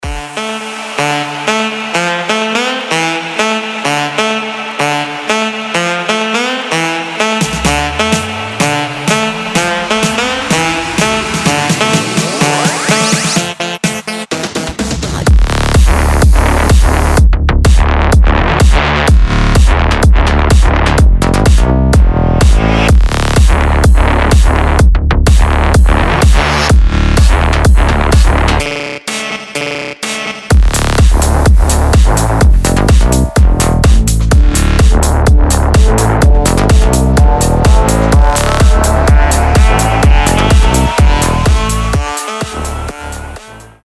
• Качество: 320, Stereo
dance
Electronic
EDM
Cover
Bass House
Саксофон
Классный кавер на трек начала 2000-х.